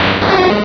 sovereignx/sound/direct_sound_samples/cries/kingdra.aif at master